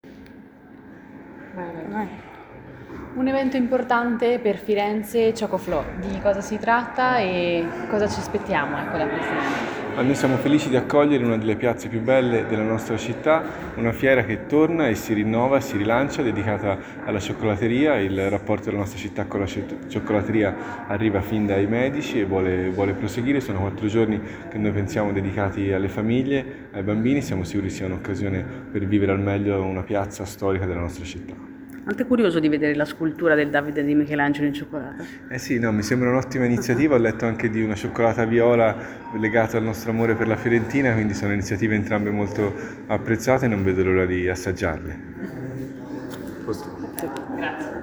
Ascolta Jacopo Vicini, Assessore Deleghe a Sviluppo economico, Turismo, Fiere e Congressi comune di Firenze:
Audio-3-Intervista-Jacopo-Vicini-1.mp3